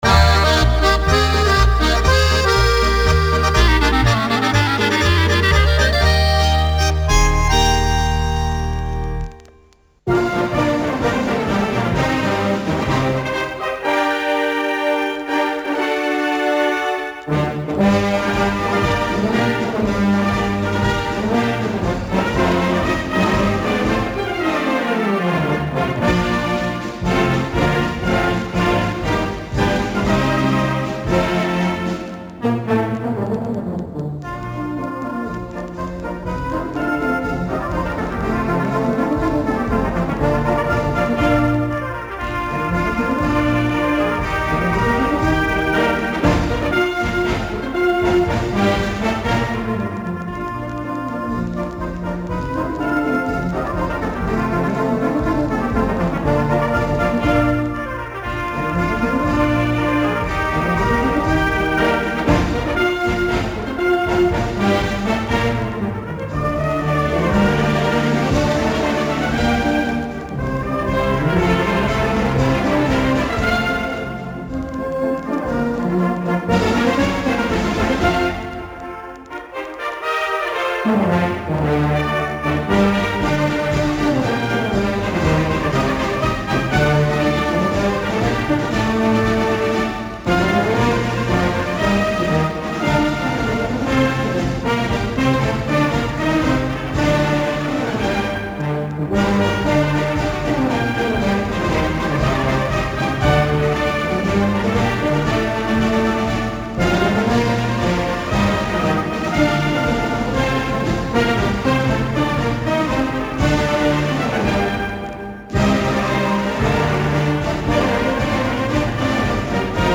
Radioaustrahlungen